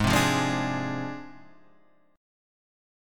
G# 6th Flat 5th